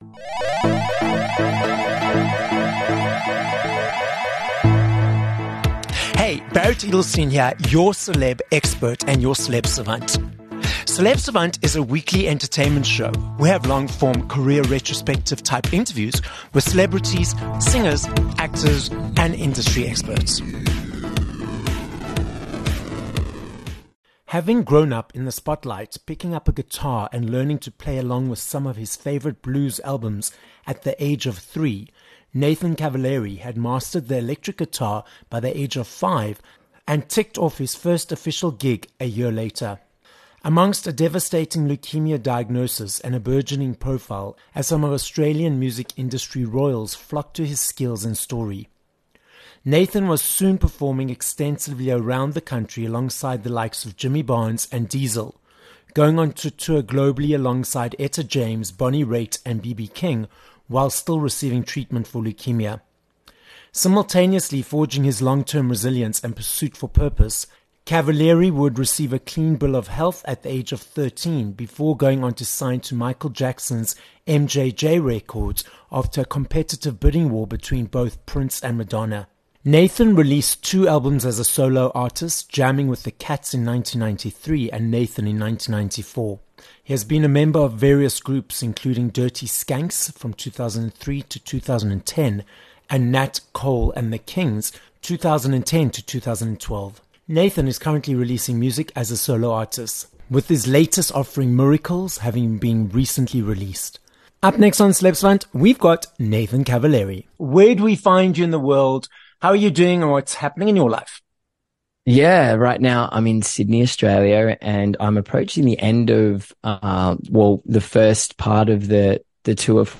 17 Dec Interview with Nathan Cavaleri
Having been madly in love with guitar as a child, Australian singer, songwriter and musician, Nathan Cavaleri joins us on this episode of Celeb Savant. Nathan explains how he was diagnosed with Leukaemia at the age of 6, that The Starlight Foundation granted him a wish to meet Mark Knopfler, which led him to perform with the likes of BB King, and ultimately be signed to Michael Jackson's MJJ Records.